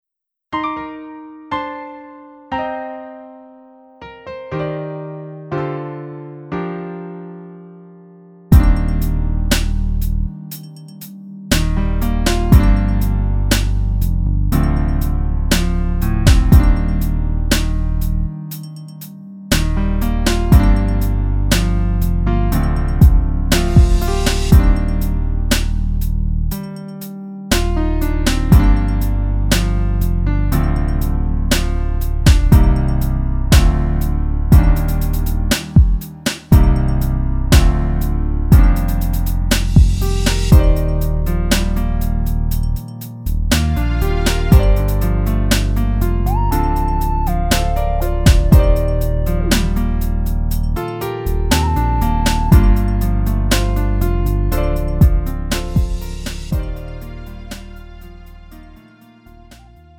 음정 원키 3:09
장르 구분 Lite MR